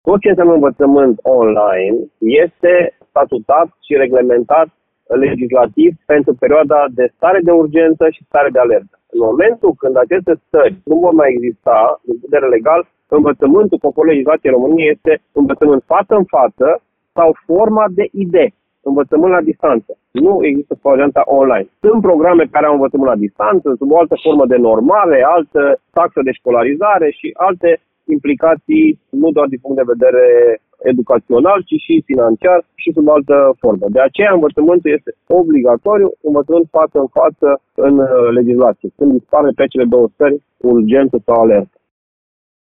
Rectorul Universității de Vest Marilen Pirtea spune că, potrivit legislației, învăţământul superior românesc aşa este conceput şi că predarea online a fost adoptată ca soluţie pentru o situaţie extremă, respectiv pandemia.